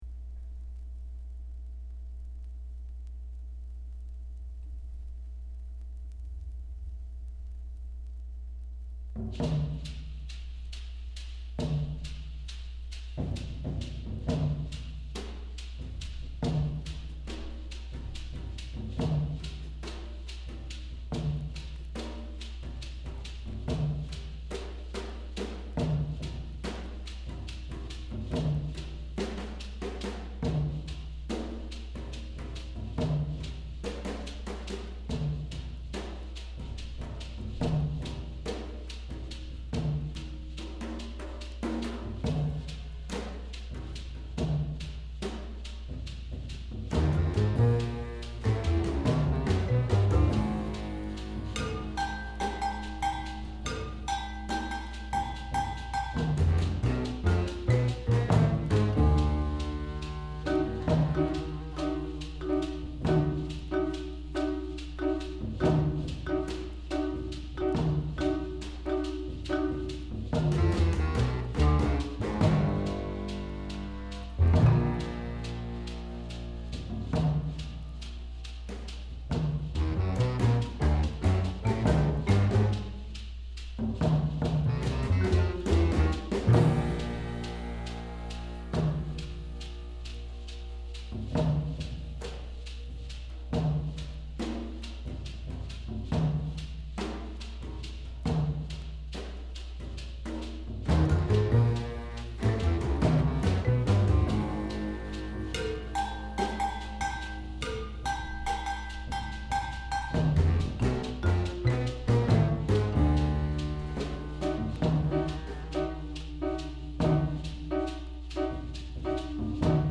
Bass Clarinet
Percussion
Timpani
Vibraphone